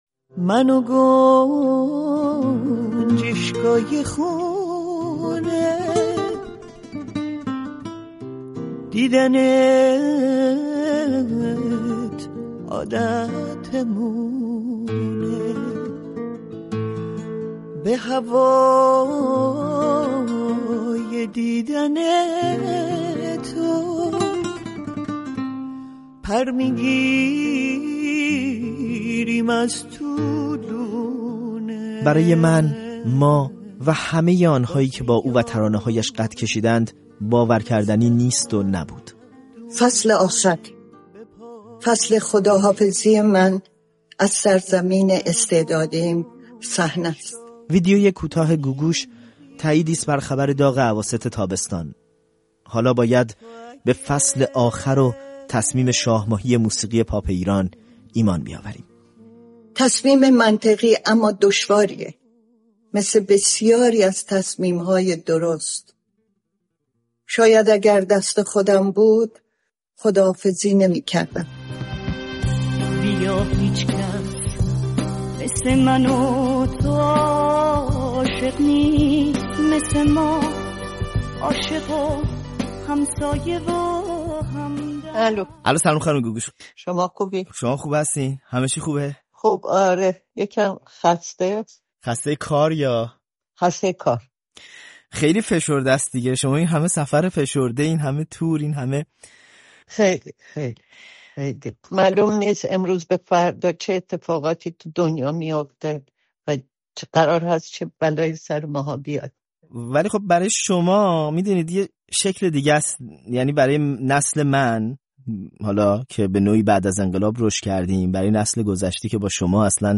«دیگر ریسک نمی‌کنم»؛ گفت‌وگوی تفصیلی با گوگوش